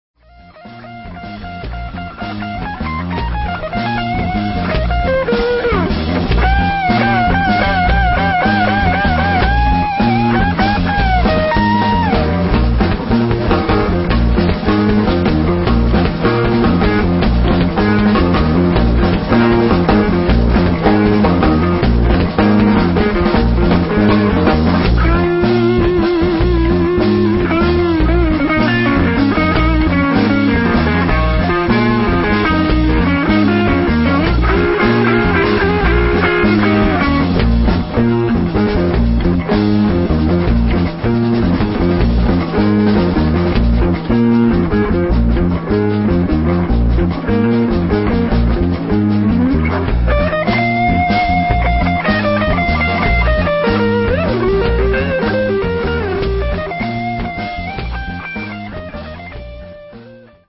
A live anthology of my work in bands from 1971 through 2001.